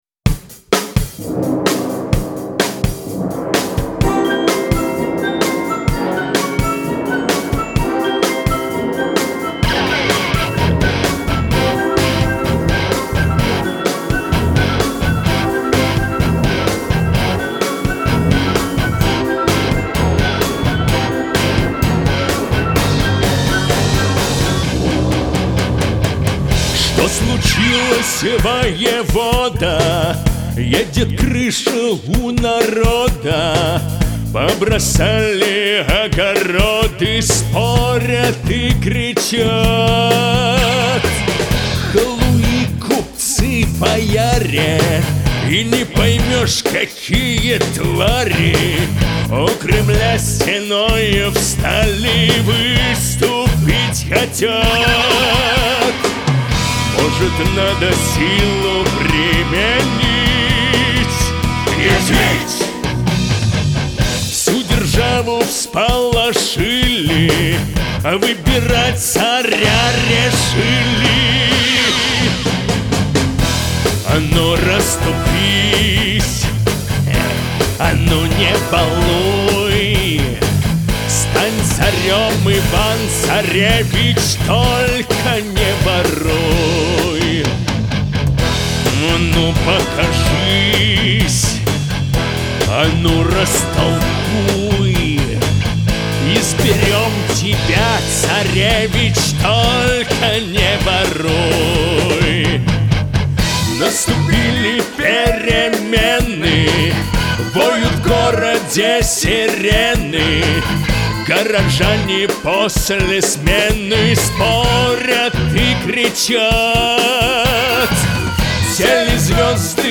гусли, бэк-вокал.